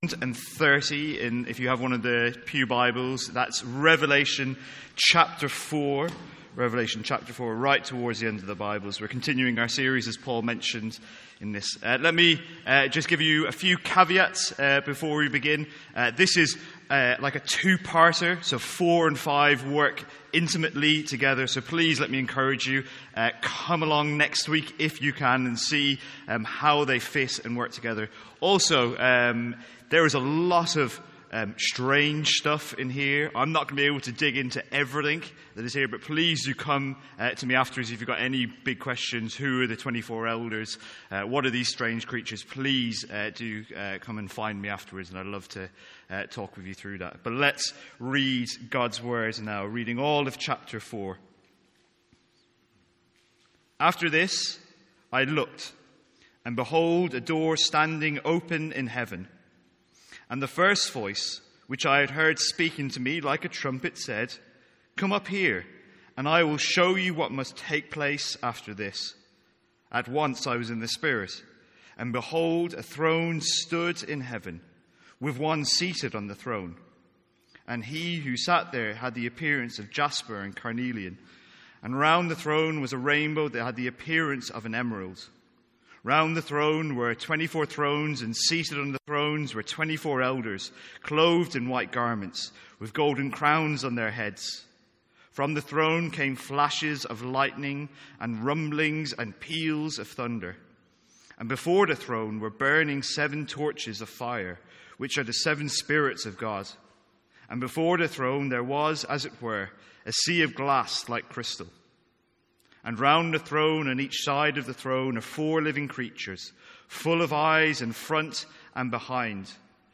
From our evening series in Revelation.